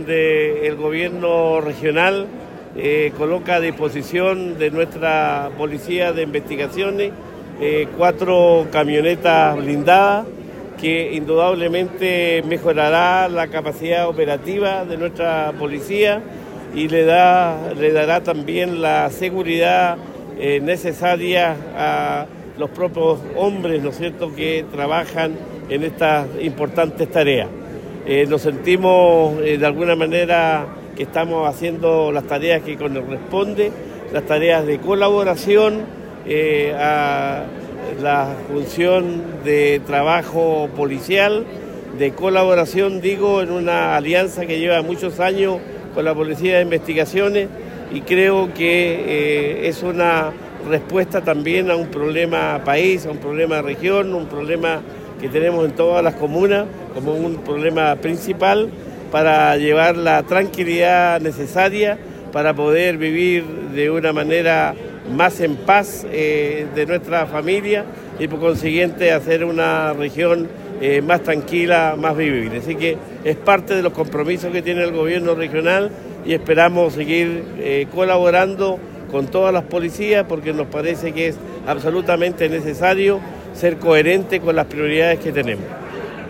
Cuña_Gobernador-Regional_entrega-de-vehículos-PDI-.wav